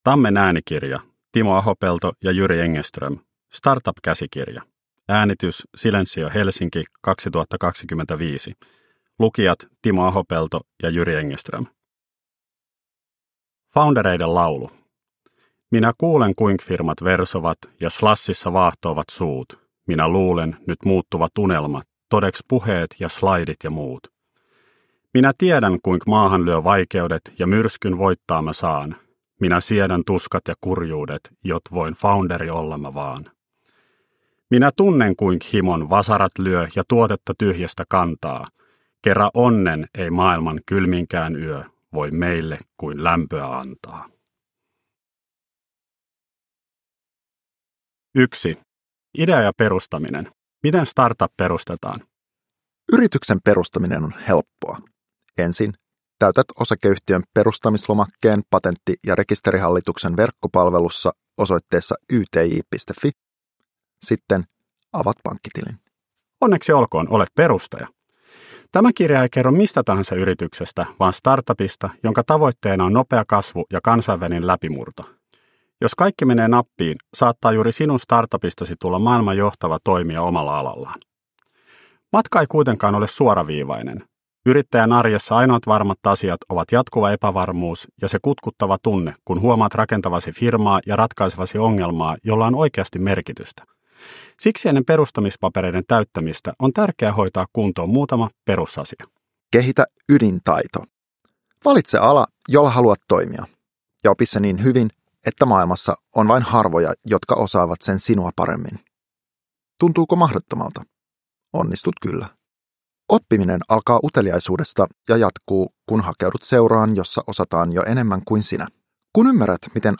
Startup-käsikirja – Ljudbok
Uppläsare: Timo Ahopelto, Jyri Engeström